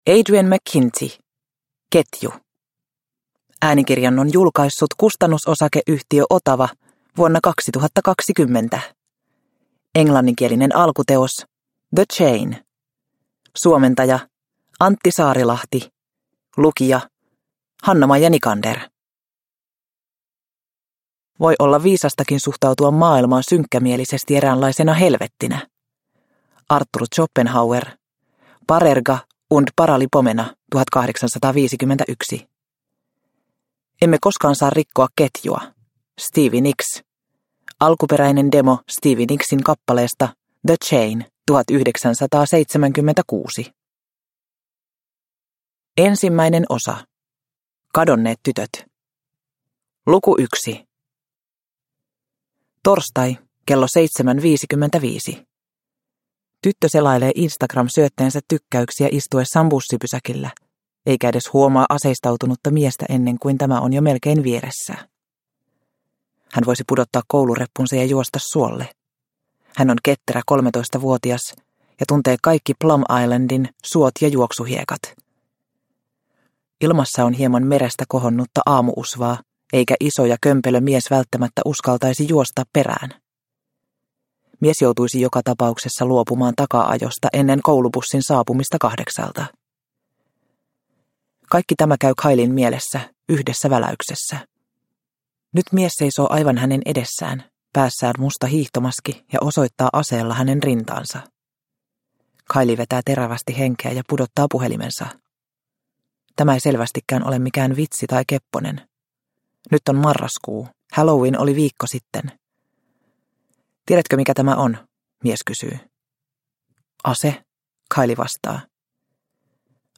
Ketju – Ljudbok – Laddas ner